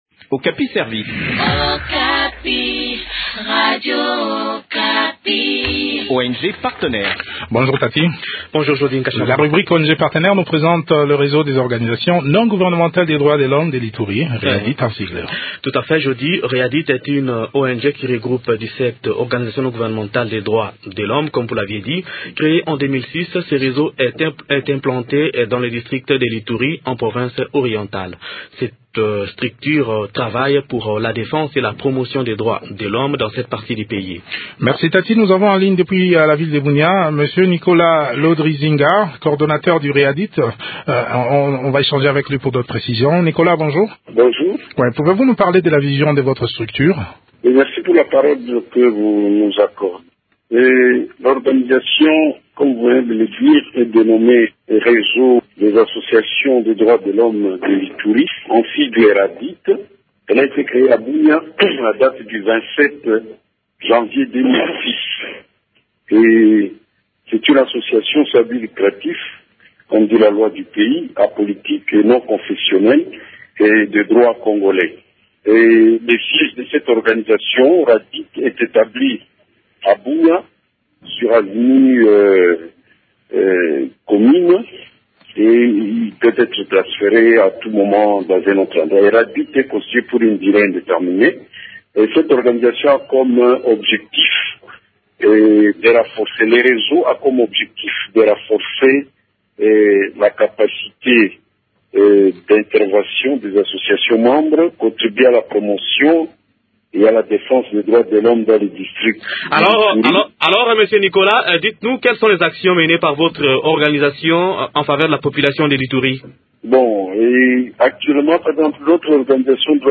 Cette structure œuvre pour la défense et la promotion des droits de l’homme dans cette partie du pays.  Découvrez les activités de cette structure dans cet entretien